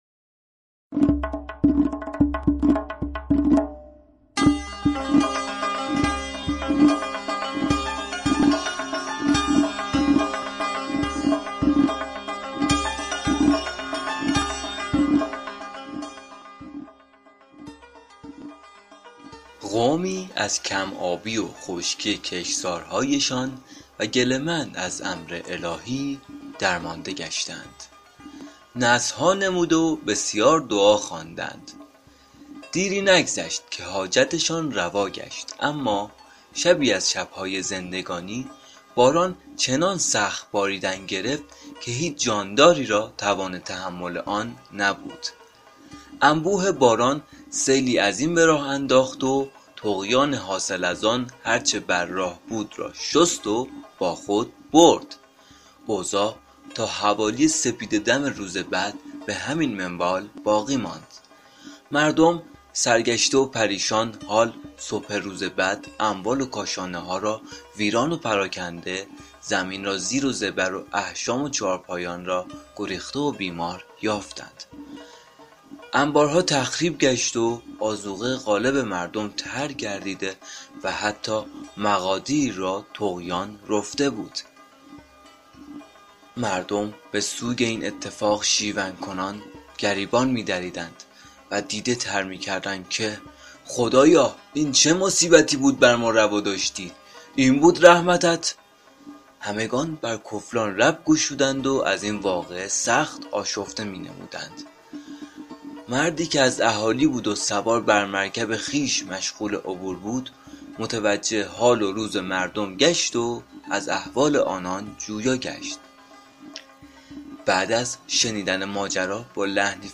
قطعه موسیقی اثر نوازندگی استاد پرویز مشکاتیان